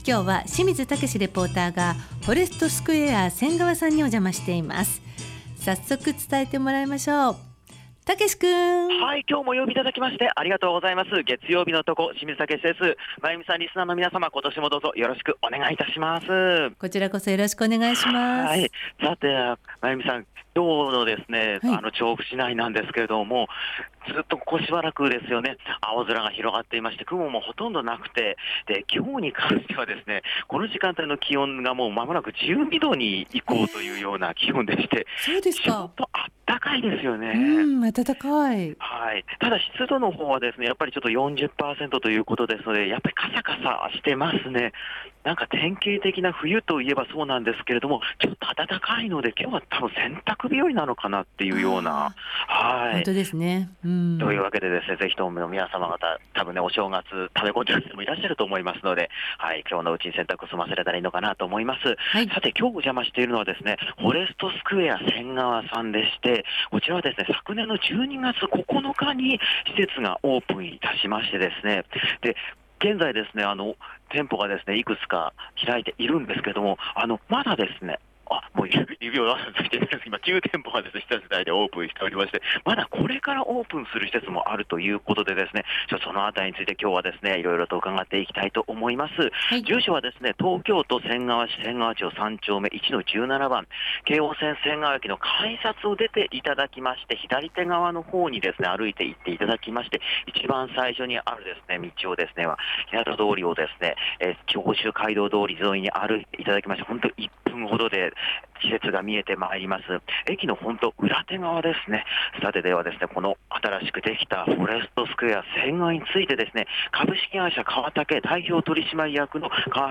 この時期としては暖かめな空の下からお届けした、本日の街角レポートは 昨年12月にオープンした仙川の新しい商業施設『フォレストスクエア仙川』さんからのレポートでした。